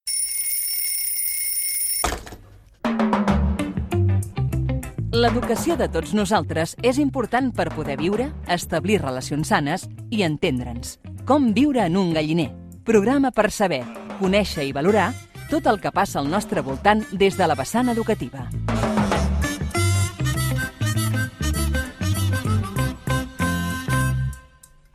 Careta del programa infantil i educatiu.
Infantil-juvenil